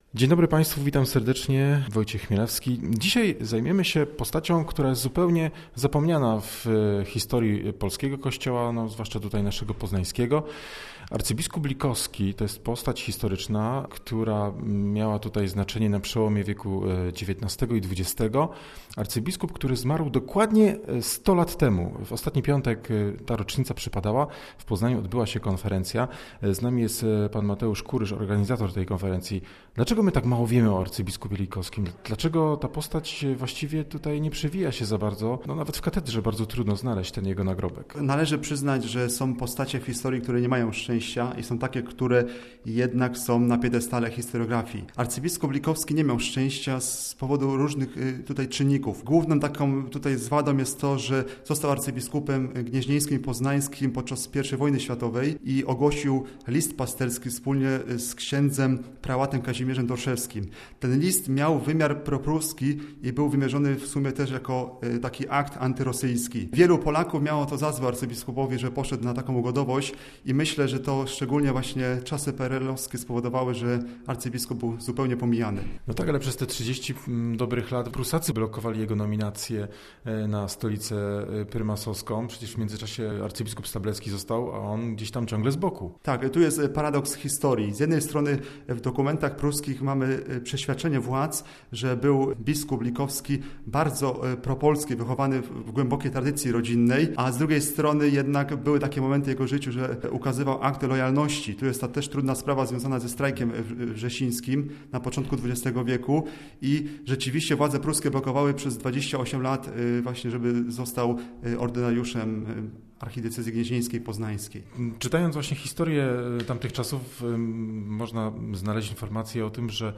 Rozmowa o prymasie Edwardzie Likowskim.